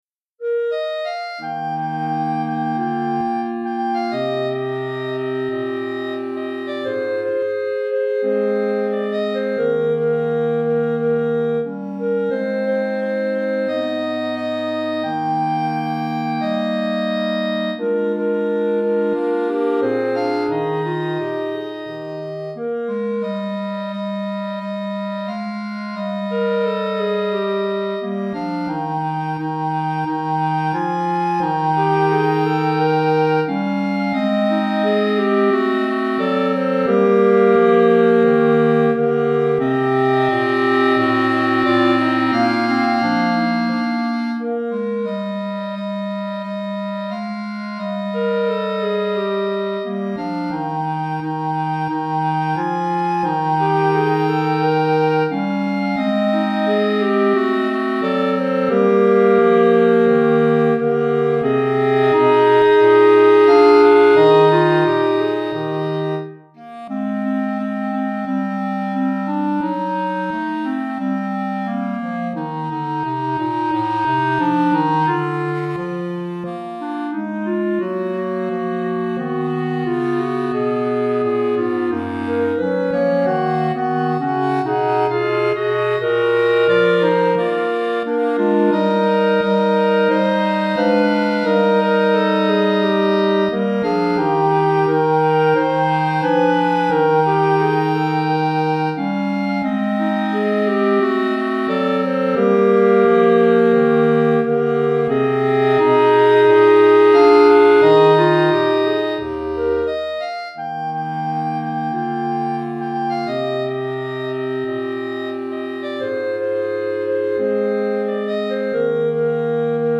3 Clarinettes en Sib et Clarinette Basse